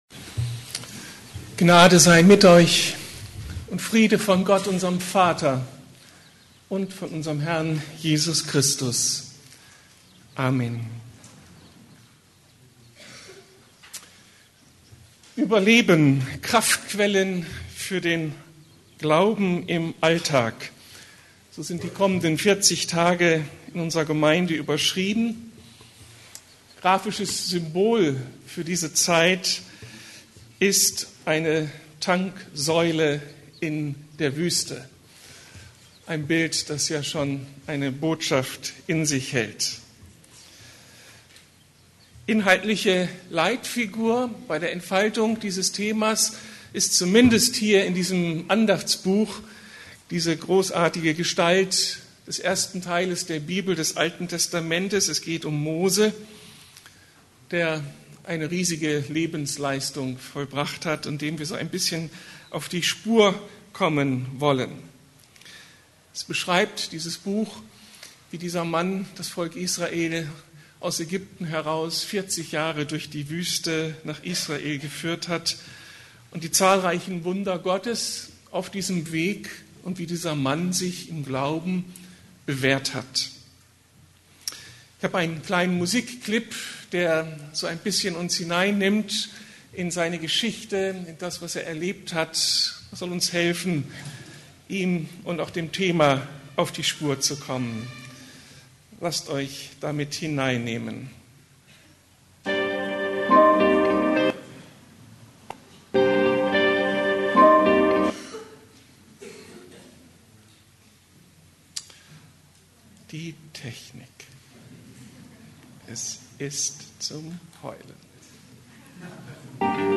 Gott hat seinen Plan für mein Leben ~ Predigten der LUKAS GEMEINDE Podcast